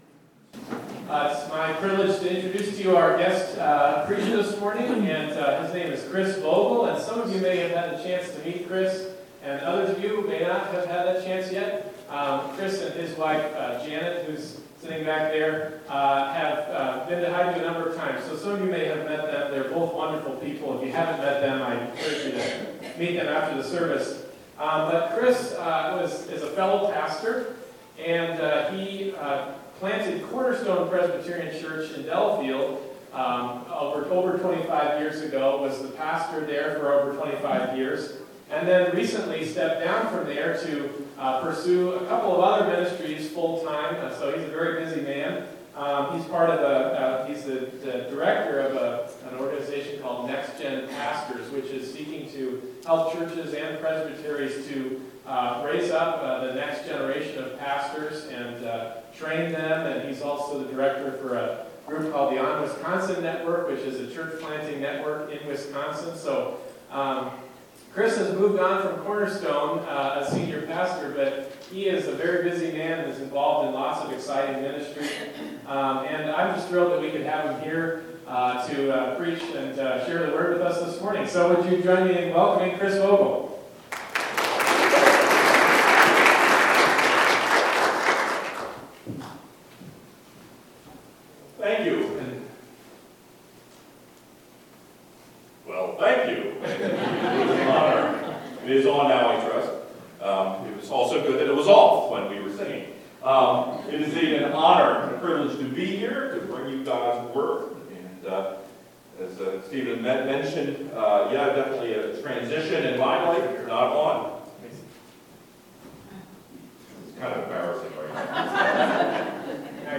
Bible Text: Matthew 20:1-16 | Preacher